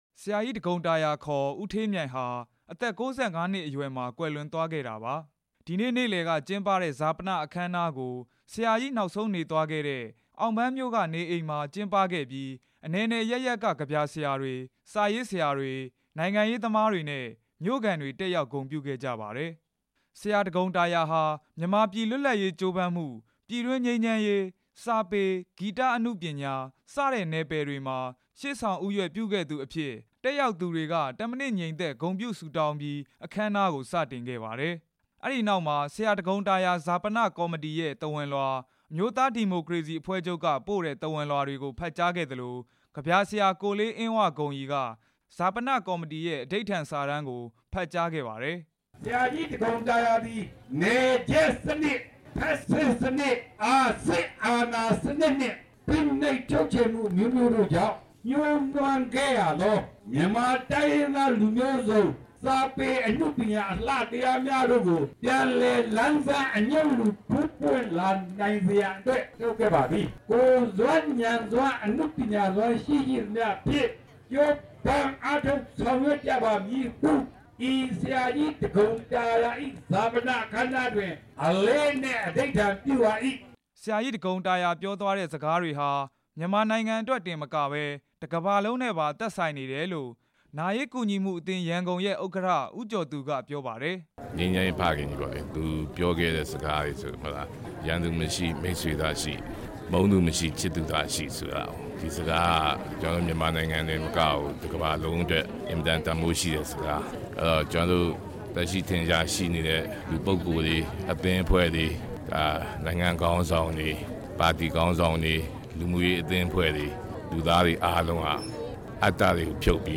ဆရာကြီး ဒဂုန်တာရာရဲ့ ဈာပနအကြောင်း တင်ပြချက်